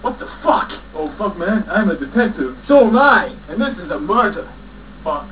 SPEECH FROM THE MOVIE :